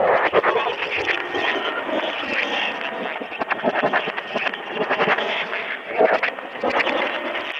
Real EVP.wav